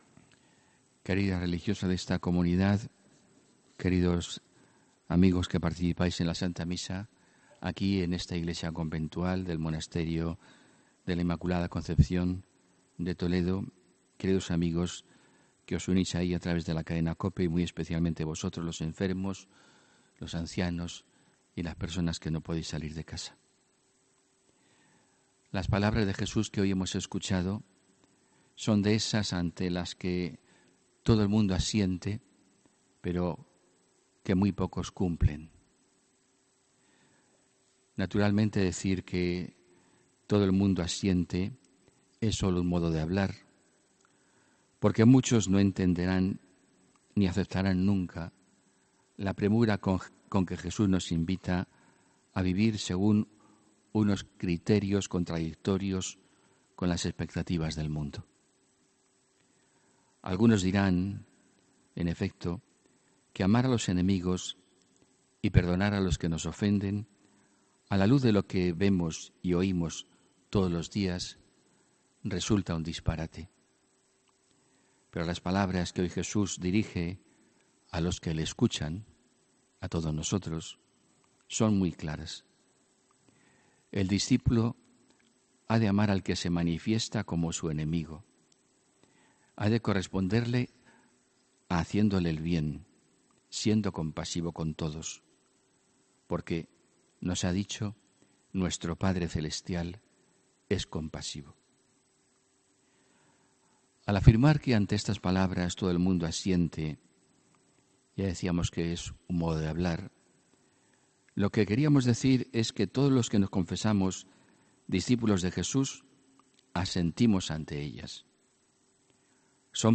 HOMILÍA 24 FEBRERO 2019